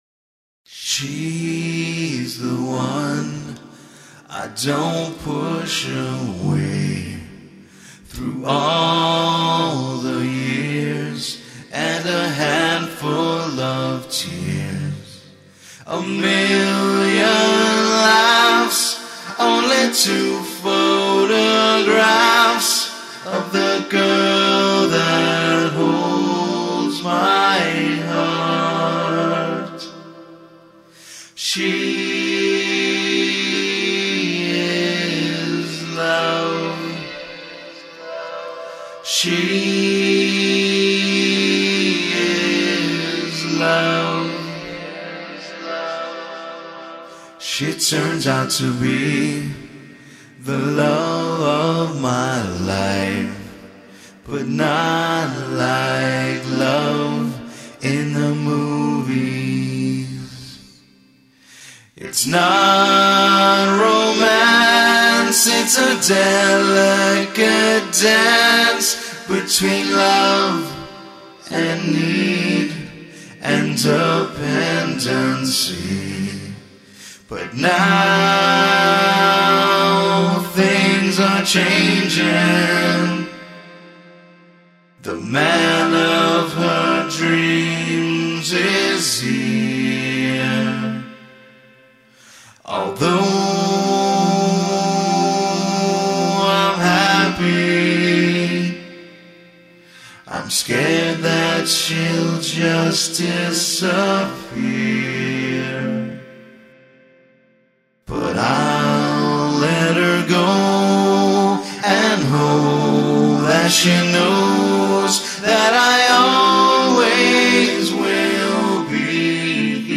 Rock & Roll
Glam trash